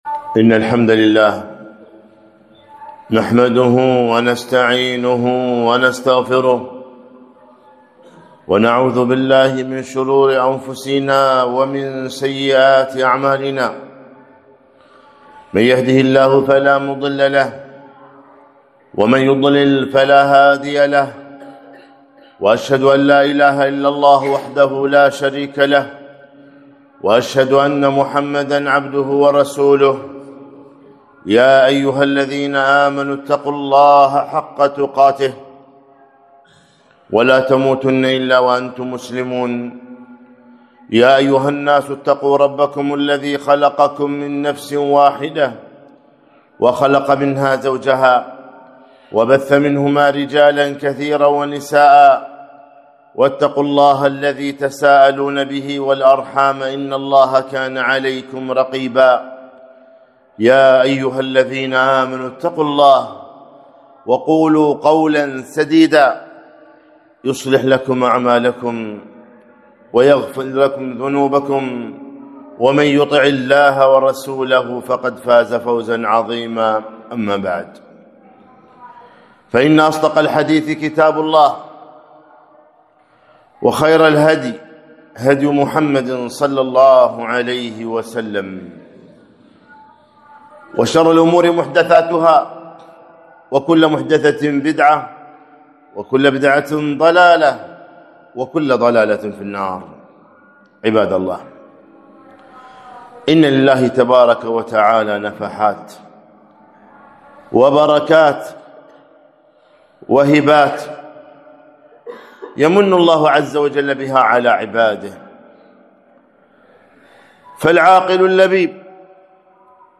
خطبة - شهر يغفل الناس عنه